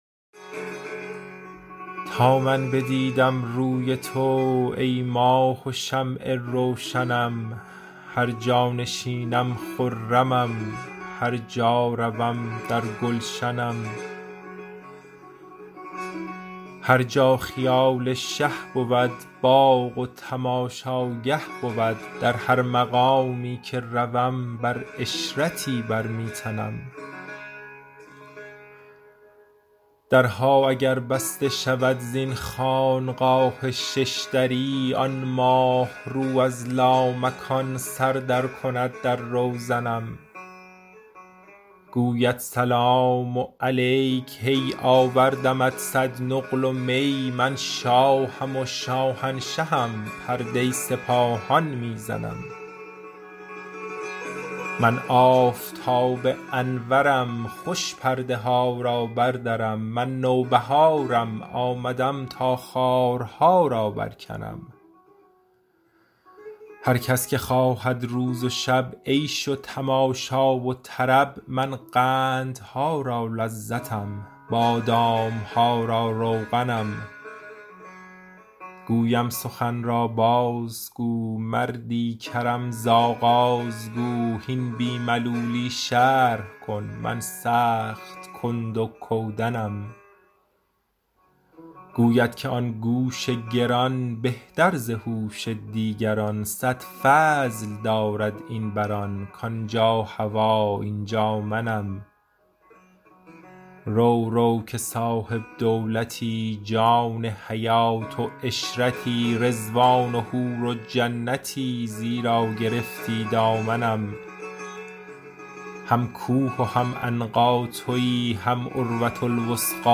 مولانا دیوان شمس » غزلیات غزل شمارهٔ ۱۳۸۳ به خوانش